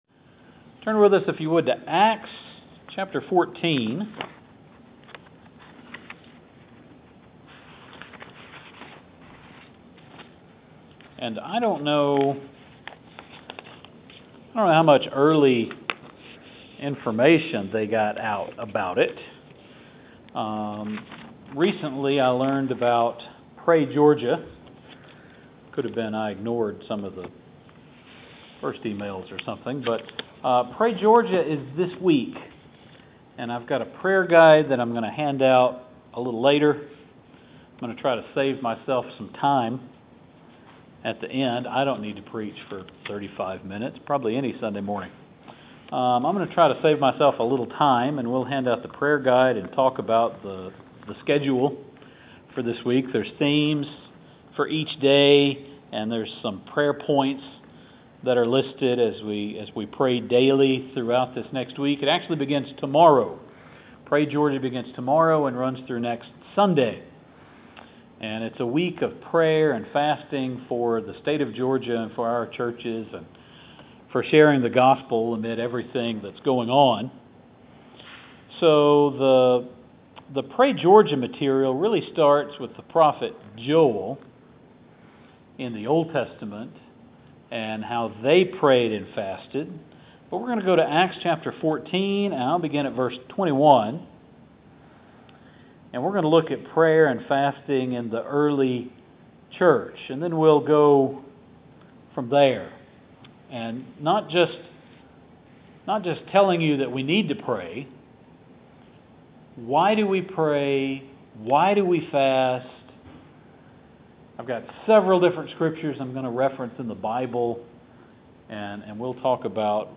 So here is sermon on why we pray and fast, based on scriptures in the Old Testament, New Testament and the teachings of Jesus in the Gospels. Instead of Joel we’ll begin in Acts chapter 14.